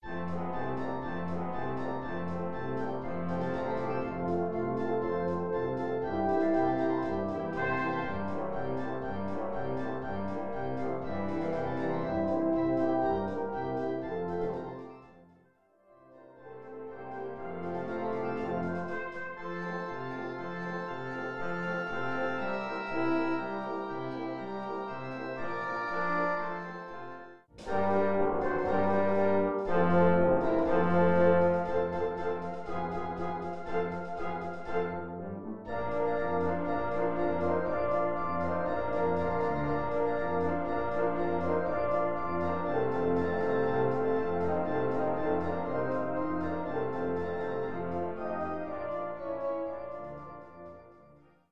gut klingende Polka im böhmischen Stil